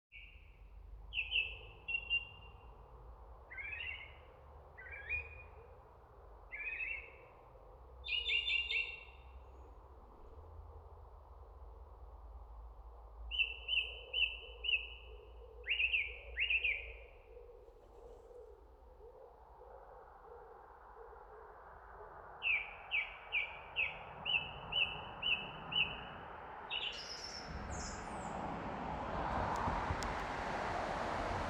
Énekes rigó (Turdus philomelos) hangja
Az énekes rigó (Turdus philomelos) hangja rendkívül gazdag, tiszta és szinte csilingelő. A madár repertoárja sokféle dallamból áll, amelyek gyorsan váltakoznak, és gyakran ismétlődnek.
A rigó éneke rövid, dallamos motívumokból áll, amelyek gyakran felfelé ívelő, trillázó hangokkal végződnek.
A dallamok általában két részből állnak: az egyik a lassabb, hosszabb, folytonos hangok sorozata, a másik pedig gyors trillákból és csattogó hangokból álló szakasz.
A hangterjedelem is figyelemre méltó: az énekes rigó képes a magas, tiszta csilingelő hangoktól a mélyebb, lágyabb tónusokig váltani, ami különleges textúrát ad az énekének.
enekes-rigo-turdus-philomelos-hangja